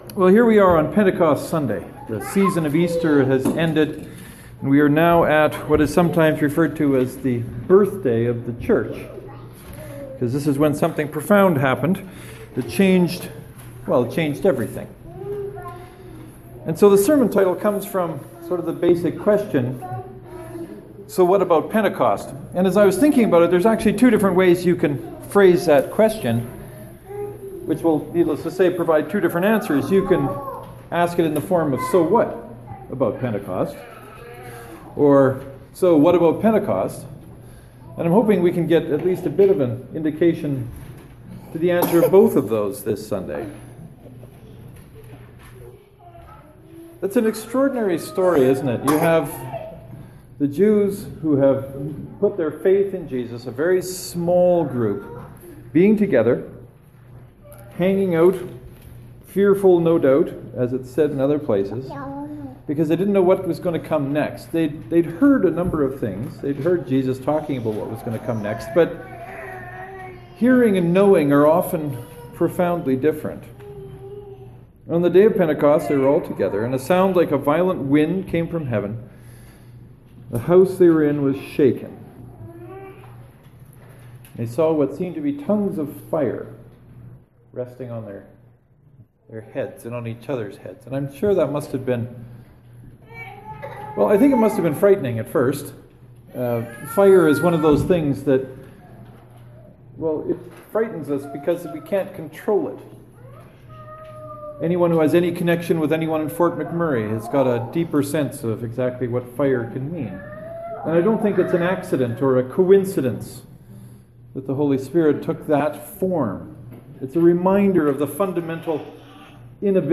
Just to clear things up from the get-go, there is only one sermon below because today was a joint service with the folks from St. Mark’s Presbyterian joining up with Knox Presbyterian congregation to celebrate the Knox Sunday School year.
Knox & St. Mark’s Presbyterian joint service So what about Pentecost?